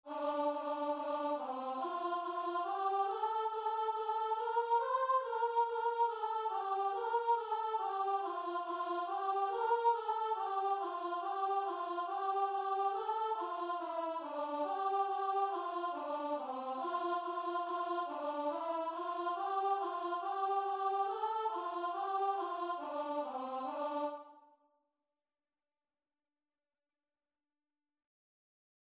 4/4 (View more 4/4 Music)
Choir  (View more Easy Choir Music)
Christian (View more Christian Choir Music)